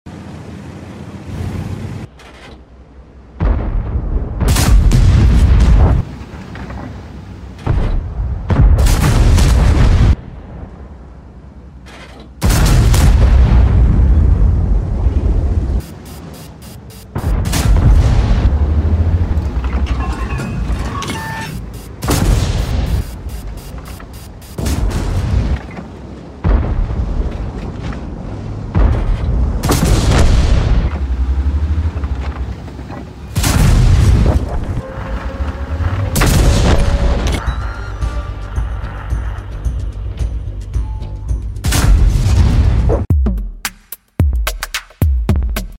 World of Tanks FV4005 Tank sound effects free download